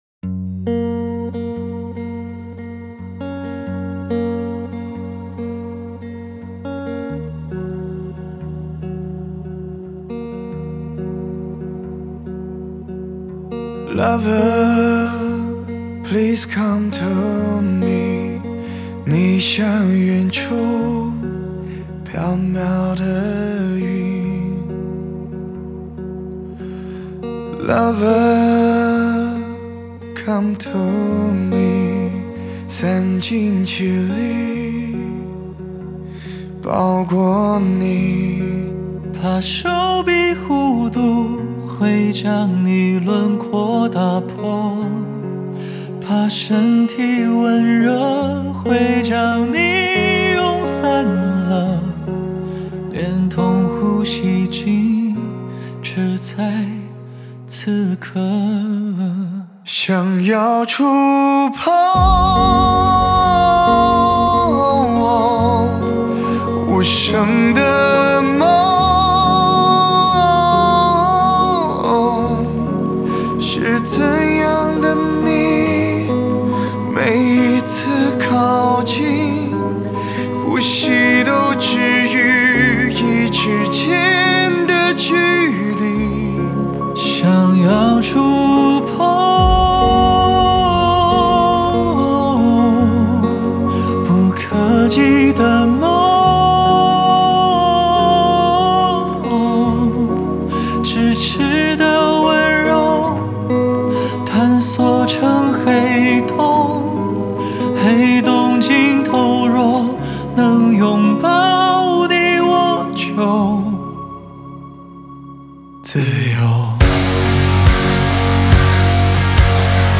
Ps：在线试听为压缩音质节选，体验无损音质请下载完整版
吉他贝斯